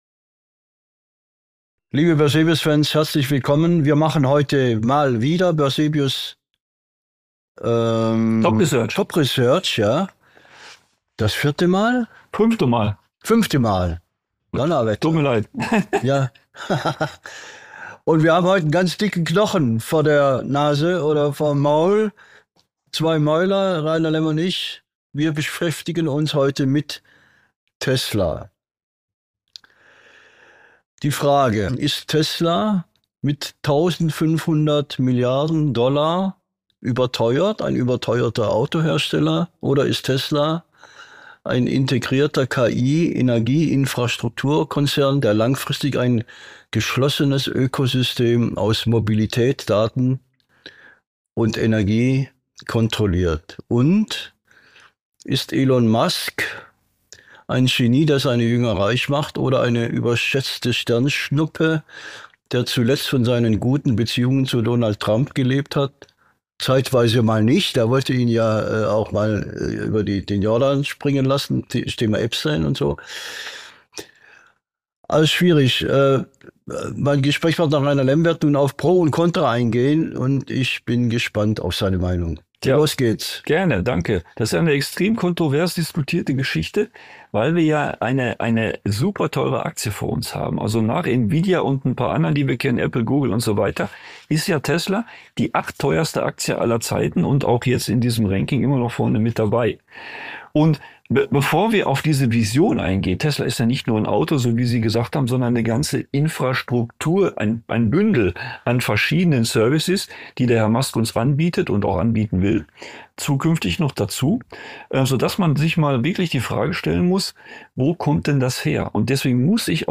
Im Mittelpunkt steht eine der größten Fragen der modernen Börse: Ist Tesla mit seiner gewaltigen Bewertung ein überteuerter Autohersteller – oder steckt dahinter die Vision eines revolutionären Technologie-Ökosystems? Gemeinsam mit einem erfahrenen Marktexperten beleuchten wir die zwei gegensätzlichen Perspektiven: Auf der einen Seite die bullishe Story rund um Elon Musk, der Tesla nicht nur als Autobauer positioniert, sondern als integrierten KI-, Energie- und Infrastrukturkonzern.